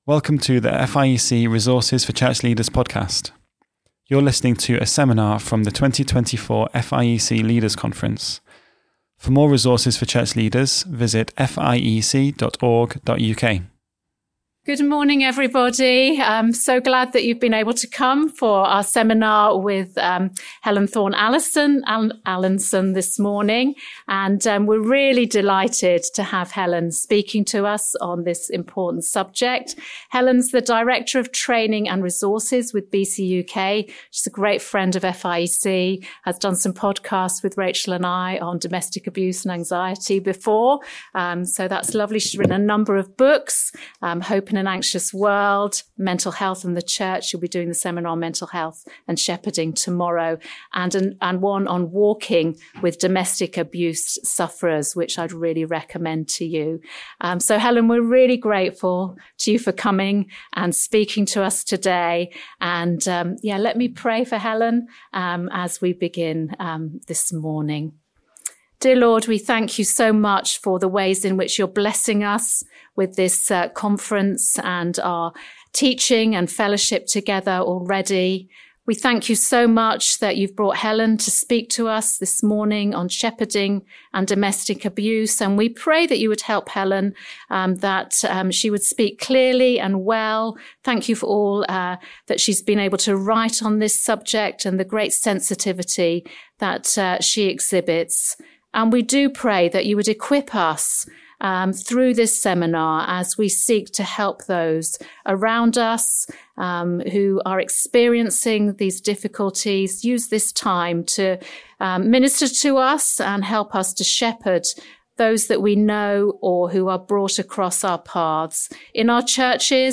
Series: Leaders' Conference 2024